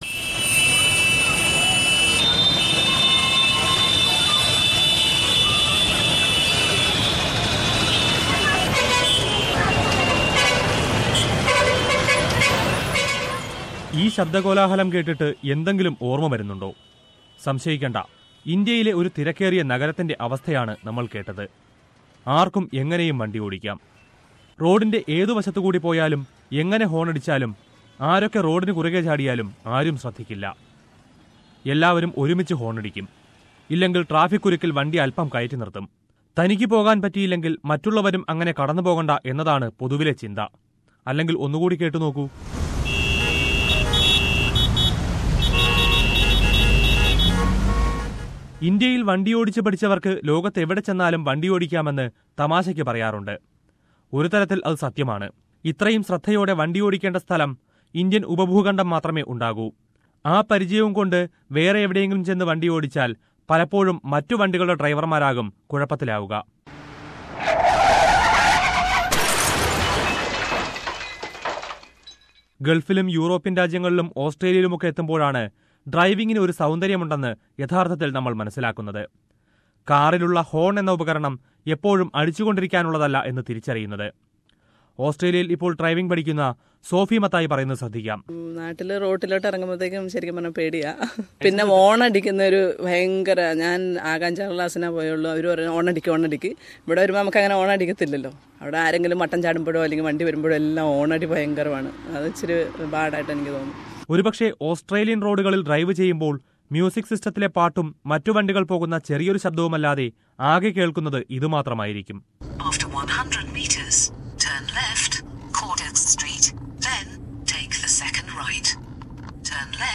But when migrating from countries like India, where driving conditions are totally different, learning Australian rules is a bit hard for many. Listen to a report...